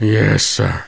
1 channel
golemDrop.mp3